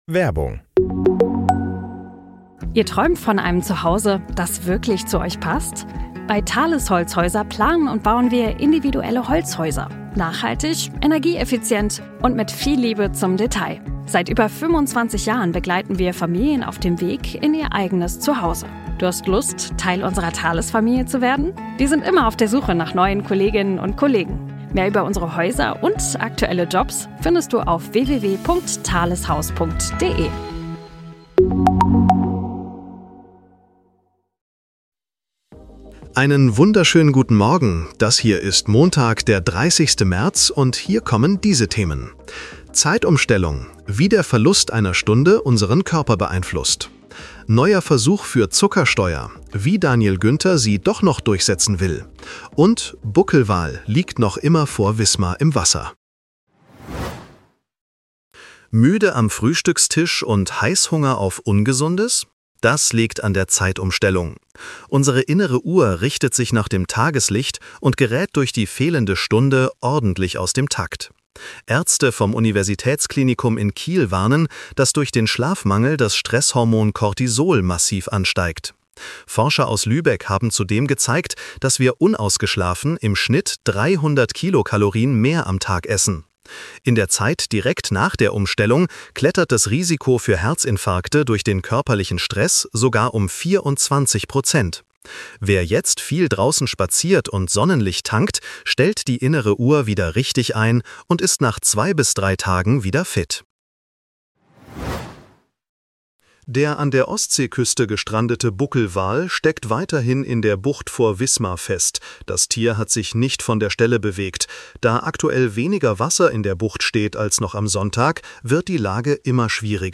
Nachrichten-Podcast bekommst Du ab 7:30 Uhr die wichtigsten